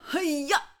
SFX_Battle_Vesna_Attack_02.wav